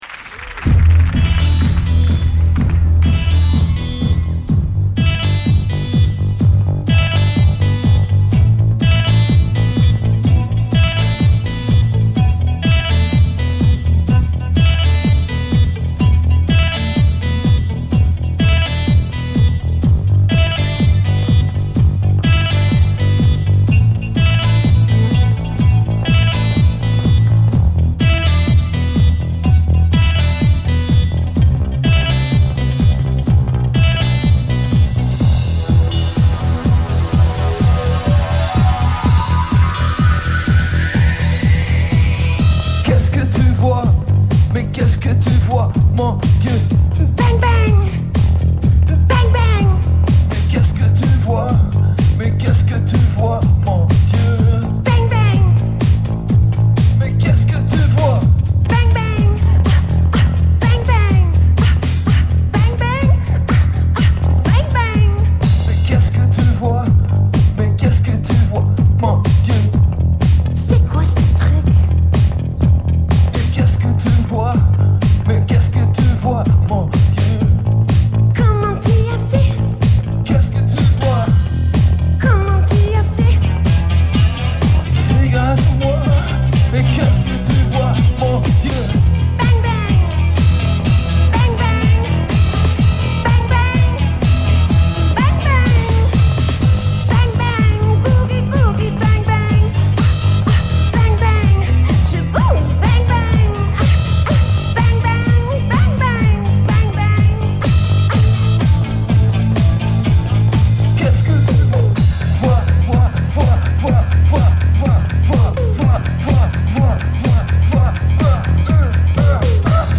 enregistrée le 28/06/2004  au Studio 105